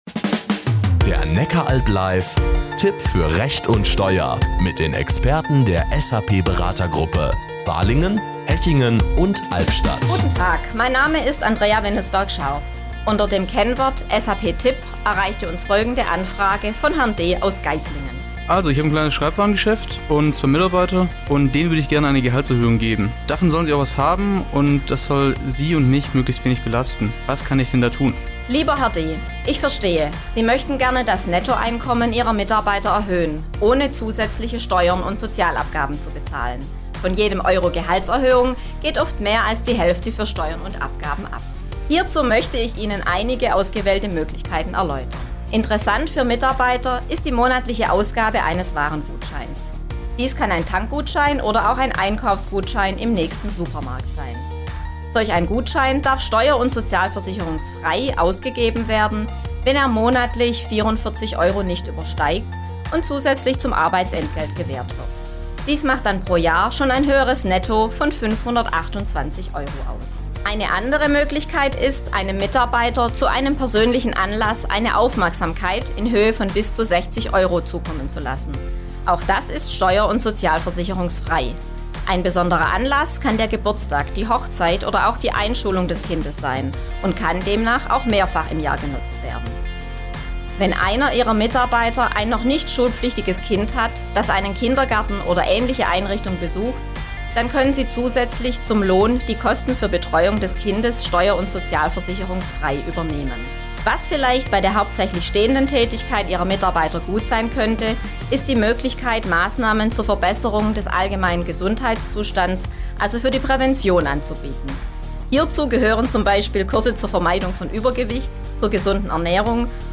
Radiospots